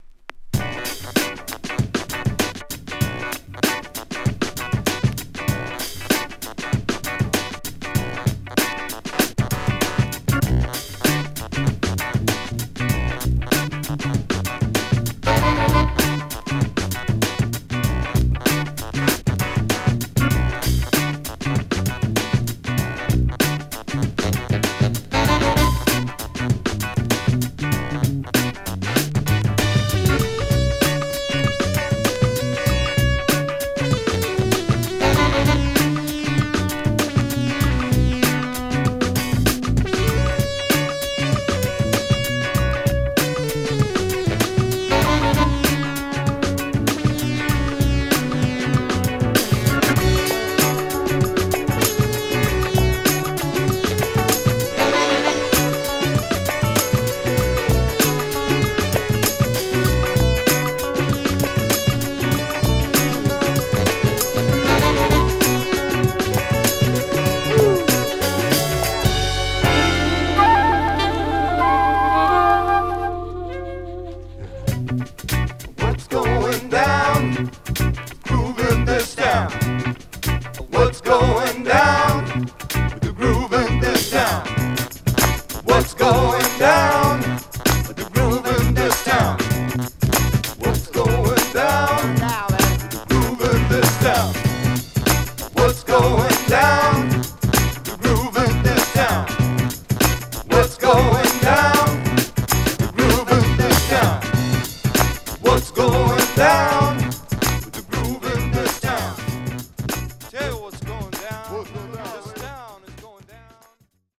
3. > JAZZ FUNK/RARE GROOVE
ファンク・ブレイクビーツラインのサイケデリック・スペースファンクアルバム !